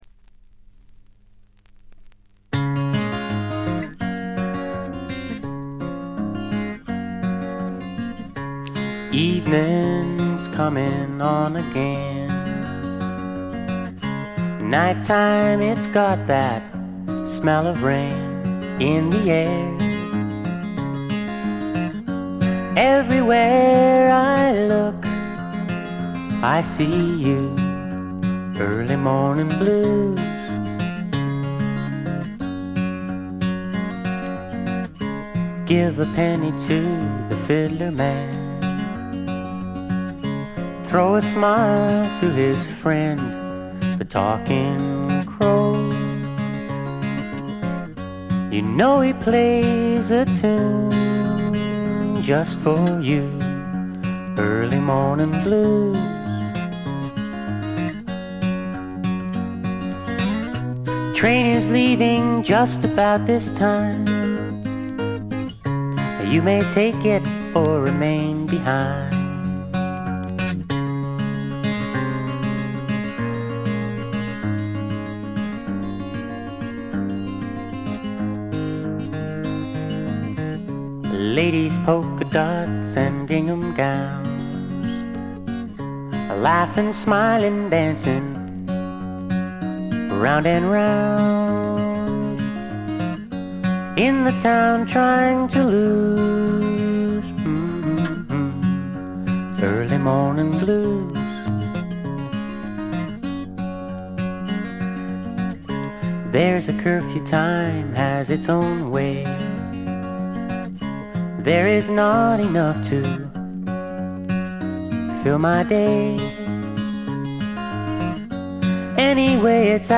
部分試聴ですが軽微なバックグラウンドノイズ程度。
試聴曲は現品からの取り込み音源です。